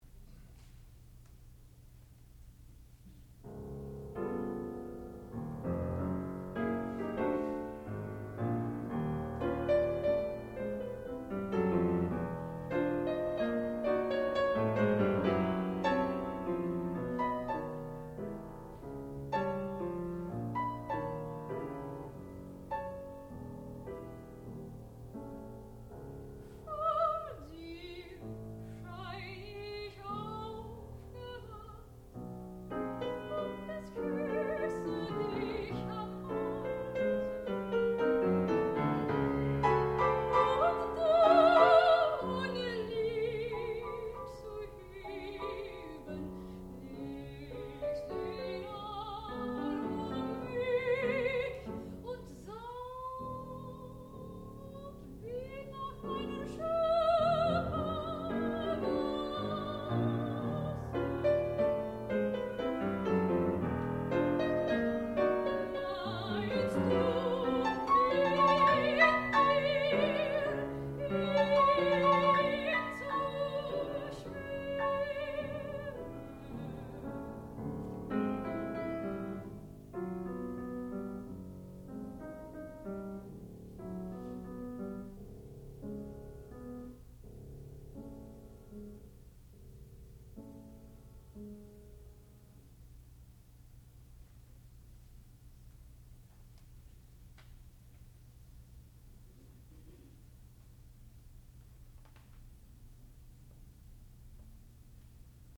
sound recording-musical
classical music
piano
soprano